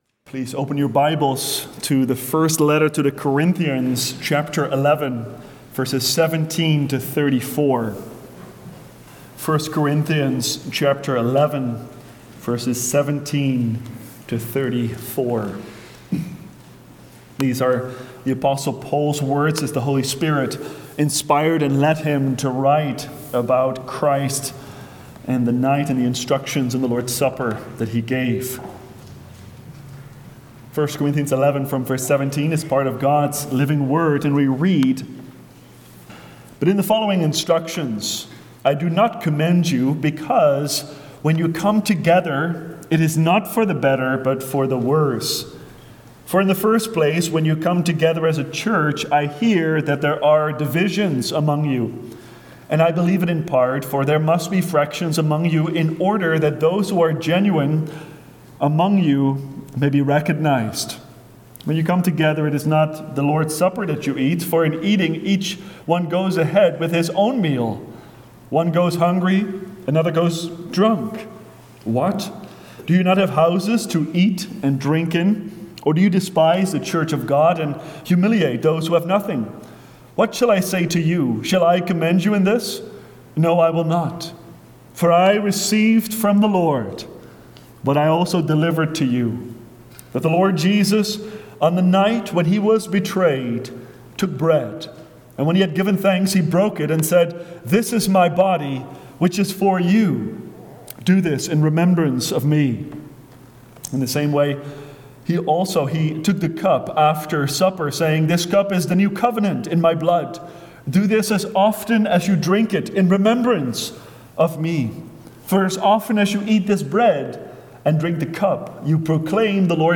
Passion and Easter Sermons